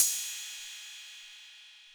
Index of /kb6/Akai_XR-20/Cymbals